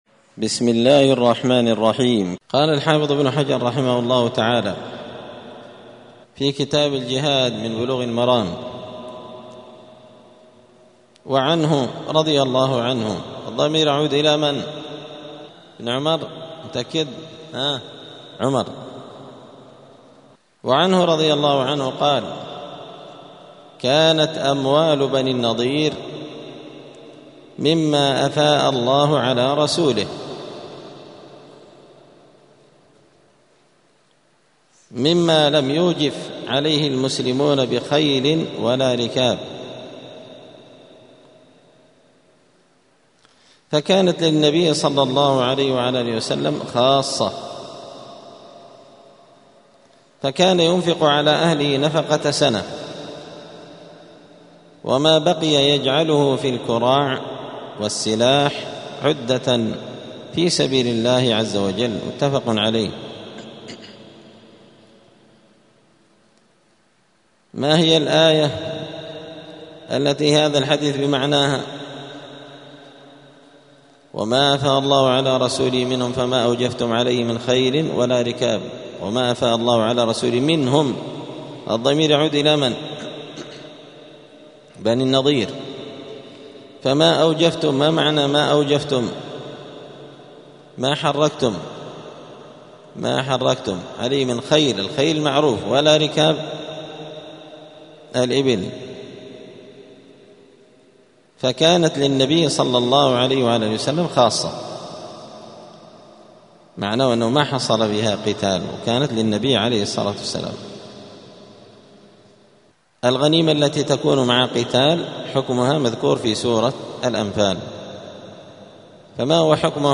*الدرس الثالث والعشرون (23) {باب إجلاء بني النظير}*
دار الحديث السلفية بمسجد الفرقان قشن المهرة اليمن